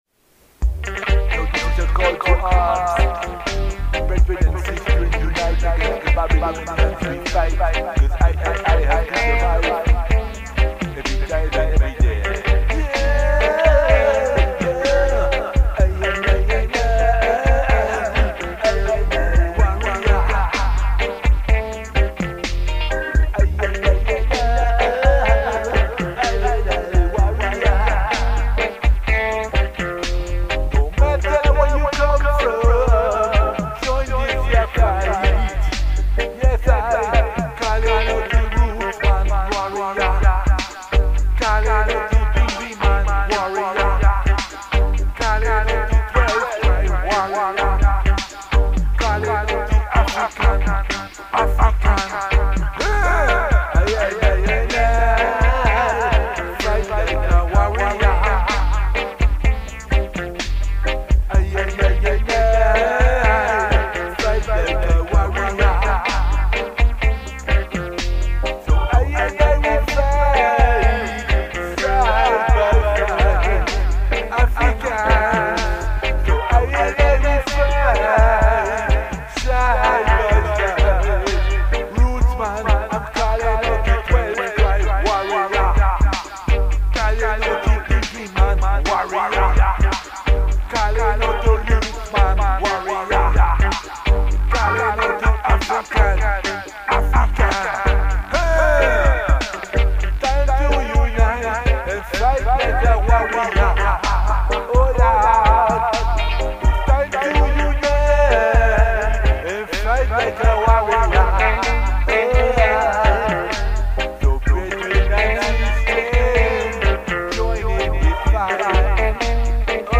transfered from cassette